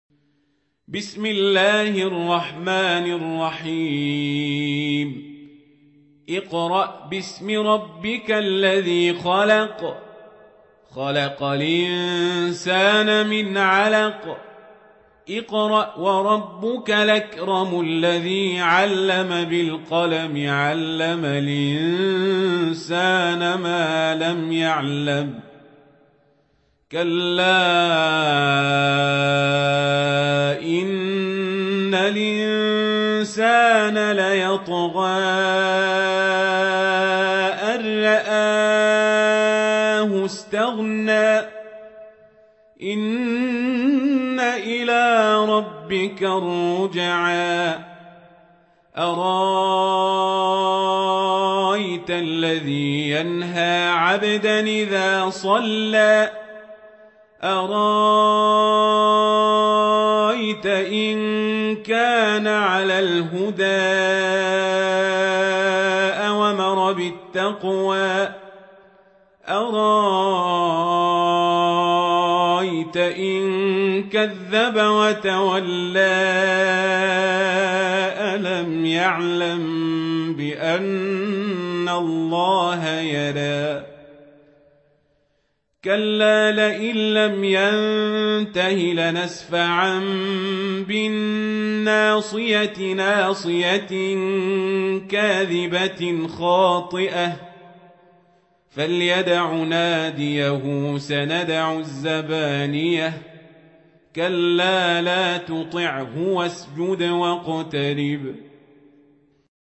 سورة العلق | القارئ عمر القزابري